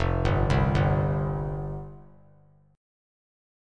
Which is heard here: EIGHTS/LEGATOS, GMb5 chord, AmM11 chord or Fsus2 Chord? Fsus2 Chord